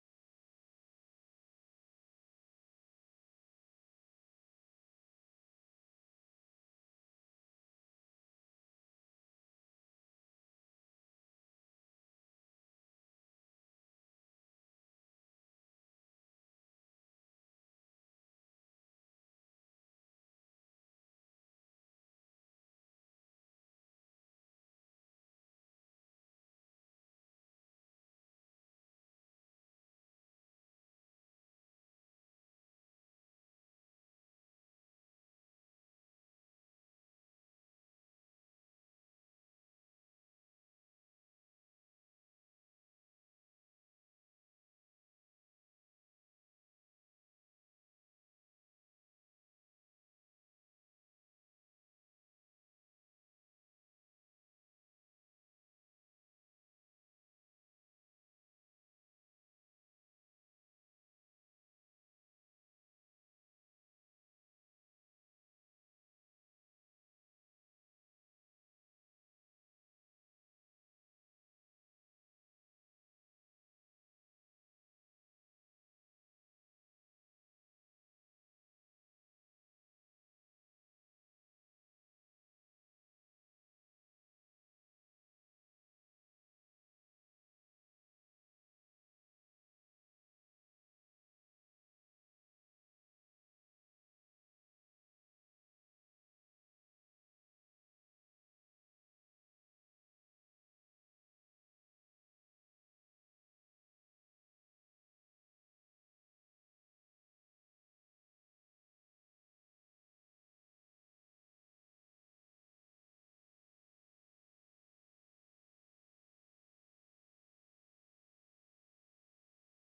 This sermon shows the potential origin of a ceremony that Jesus used to proclaim how God's spirit would be available to all during the 1.000 years.